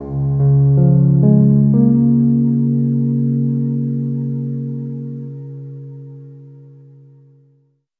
Reverb Piano 13.wav